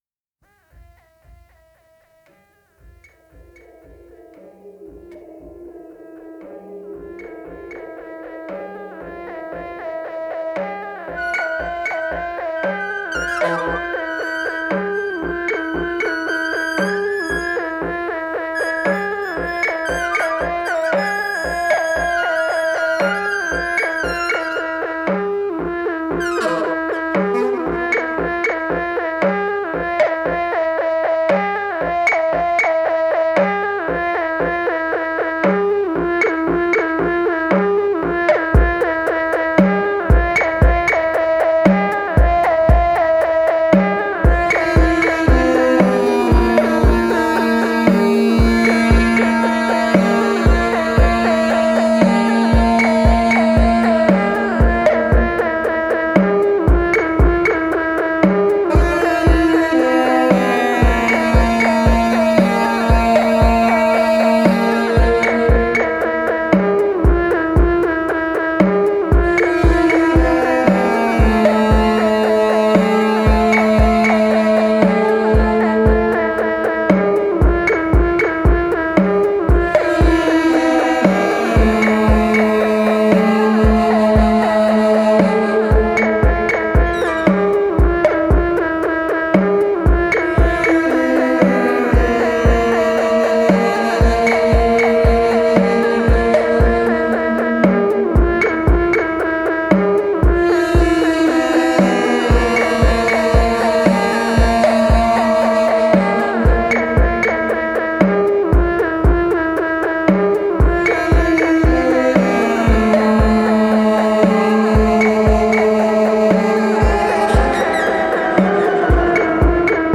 avant exotica
surreal trip